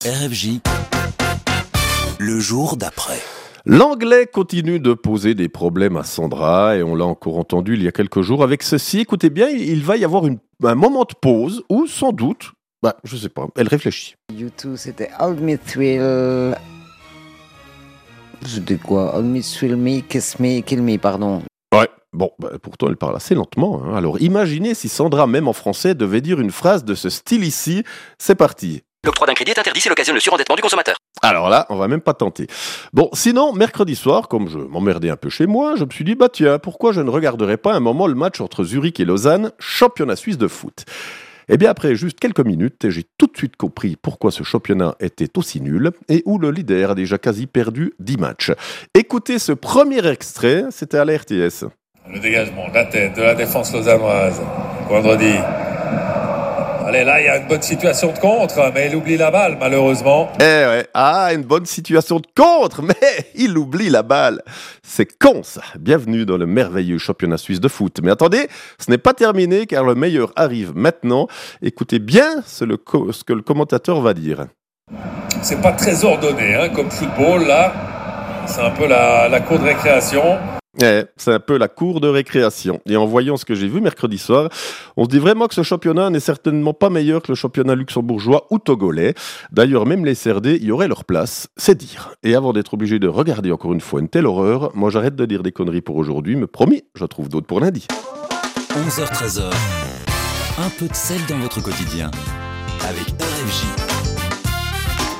L'actu de la veille, traitée de manière un peu décalée, sons à l'appui, c'est ça "Le jour d'après !"